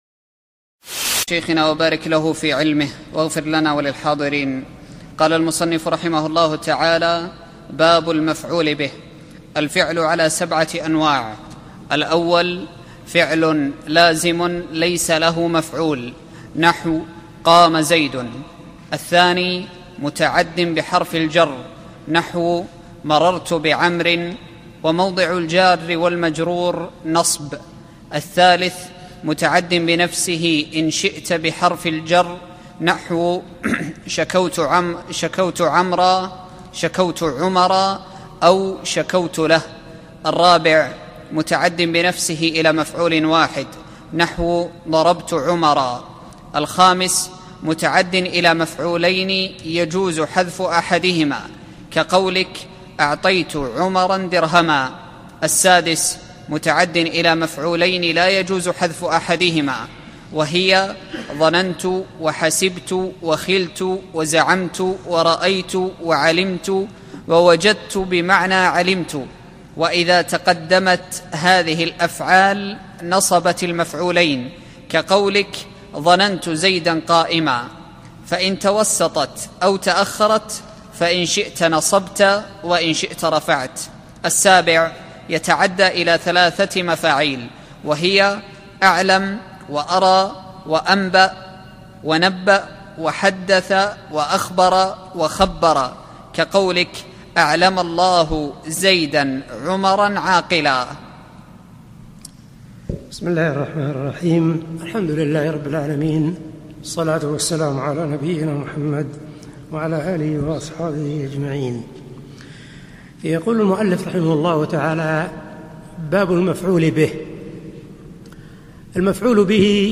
الدورة العلمية 15 المقامة في جامع عبداللطيف آل الشيخ في المدينة النبوية لعام 1435
الدرس الرابع